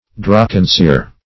Drawcansir \Draw"can*sir\, n. [From the name of a bullying